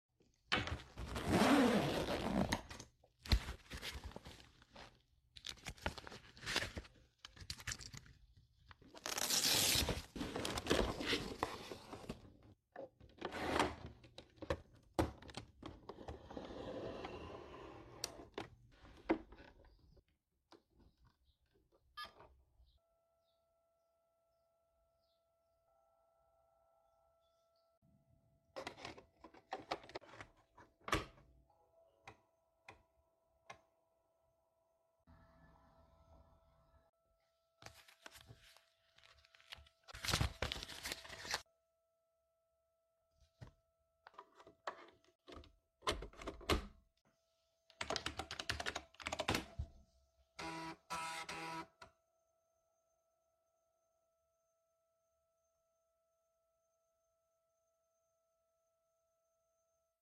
IBM PC Convertible 5140, April sound effects free download